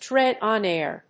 アクセントtréad on áir